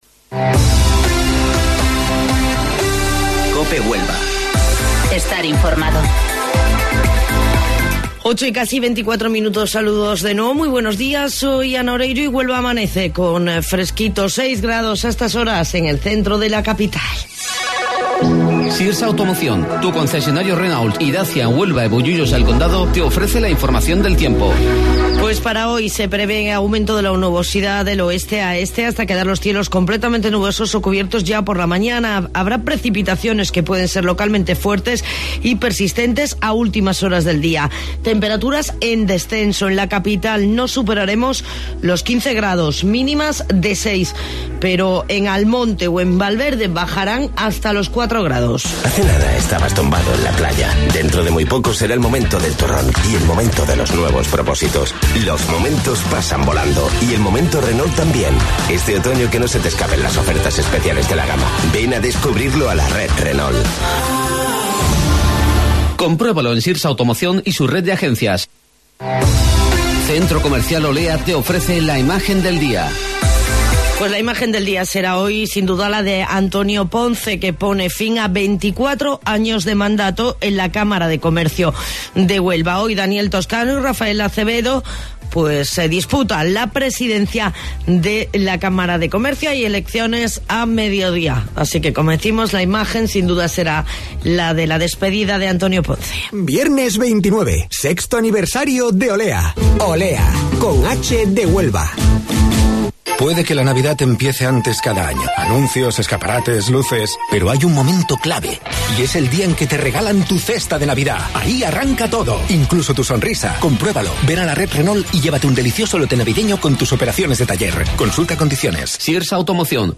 AUDIO: Informativo Local 08:25 del 19 de Noviembre